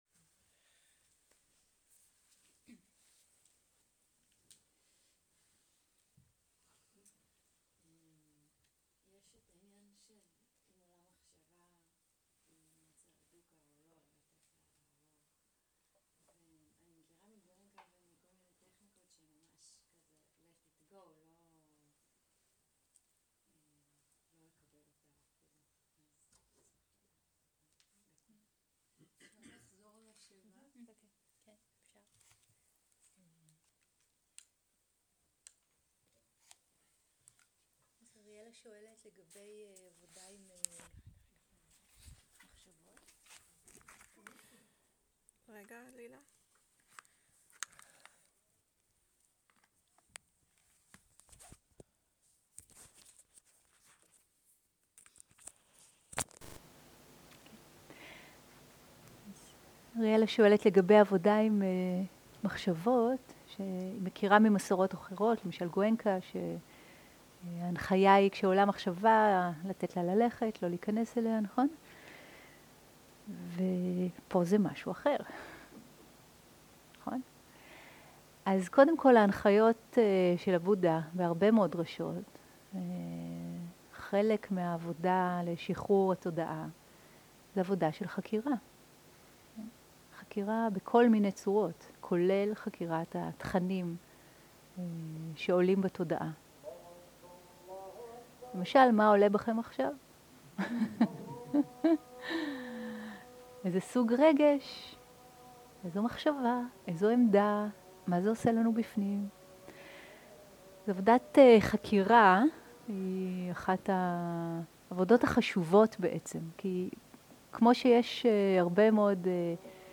סוג ההקלטה: שאלות ותשובות שפת ההקלטה